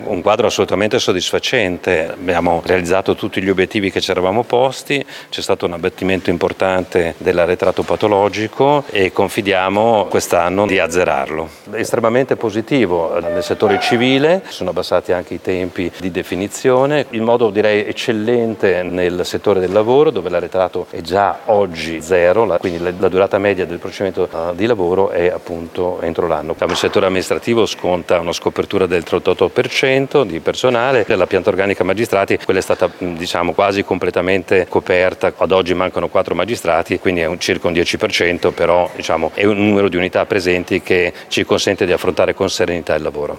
Il presidente del tribunale di Modena Alberto Rizzo